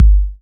SHORT BB KIK.wav